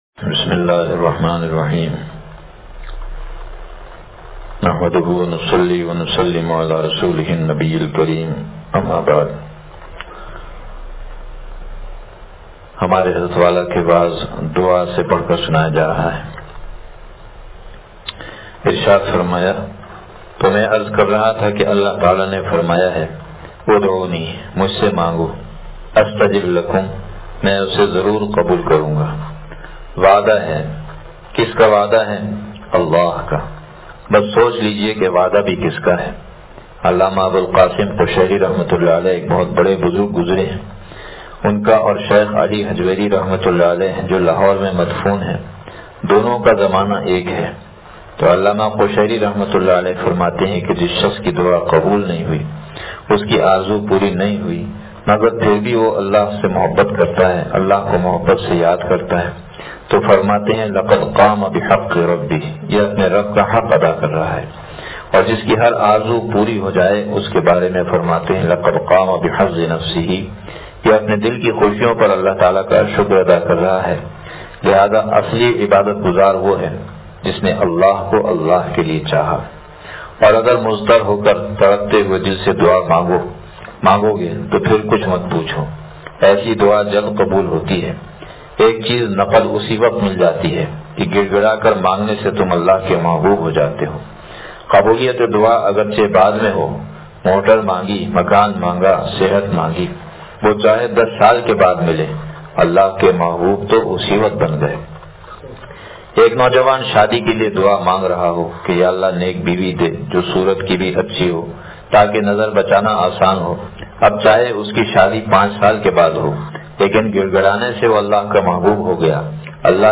شیخ العرب والعجم عارف باللہ مجدد زمانہ حضرت والا رحمتہ اللہ علیہ کا وعظ دعا سے پڑھا